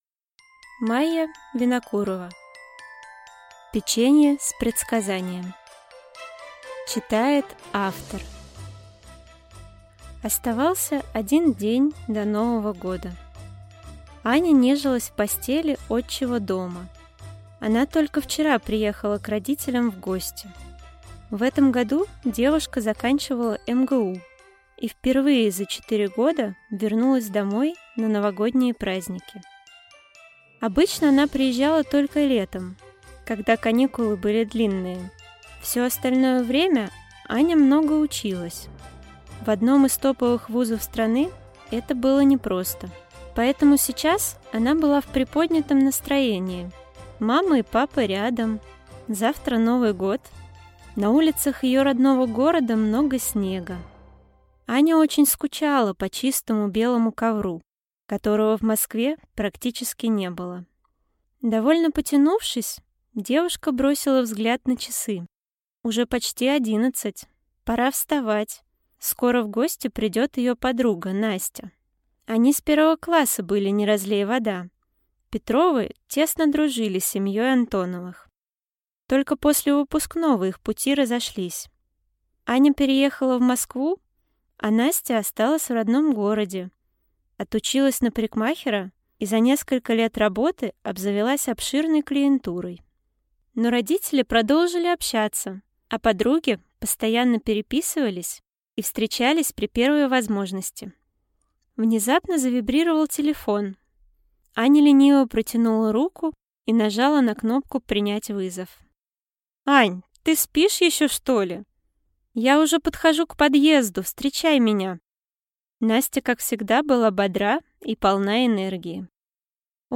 Аудиокнига Печенье с предсказанием | Библиотека аудиокниг